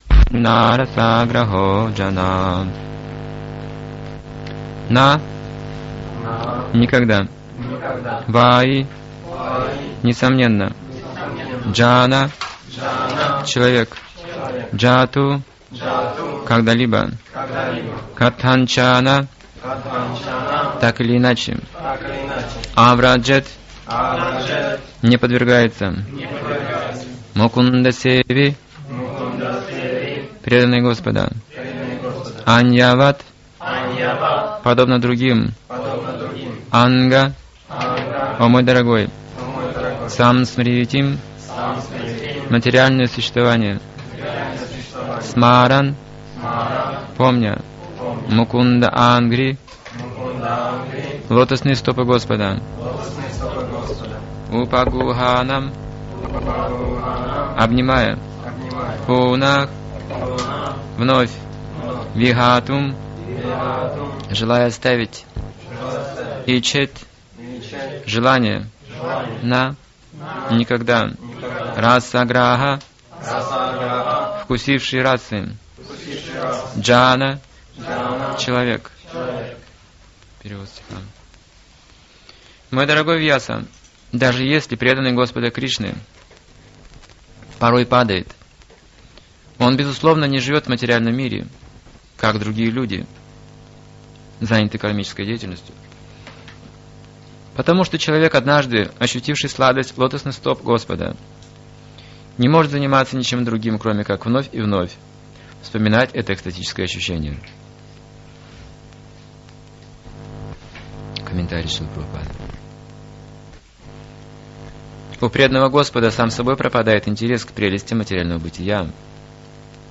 ШБ 1.5.19 (Воронеж)